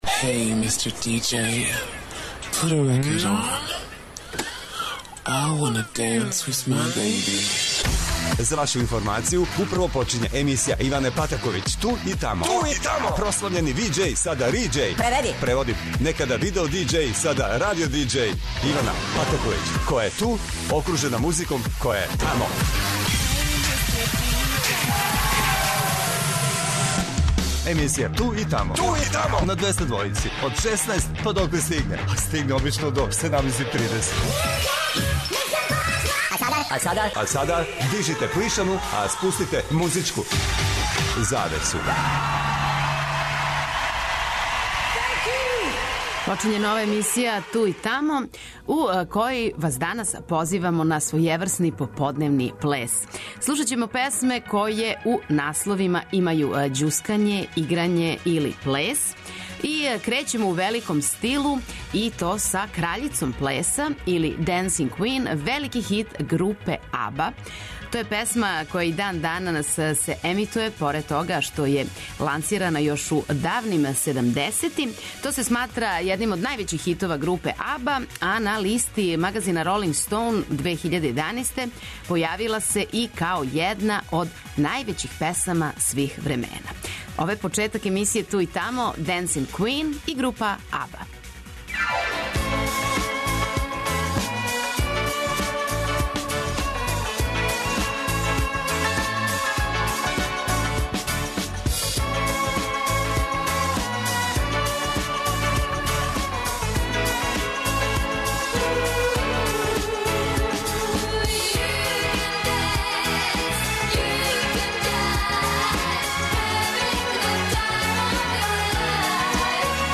Ново издање емисије 'Ту и тамо' биће својеврсни поподневни плес. На 'денс' списку су велике музичке звезде са песмама у чијим насловима се помињу играње и ђускање.
Очекују вас велики хитови, страни и домаћи, стари и нови, супер сарадње, песме из филмова, дуети и још много тога.